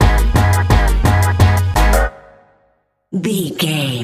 Aeolian/Minor
G#
drum machine
synthesiser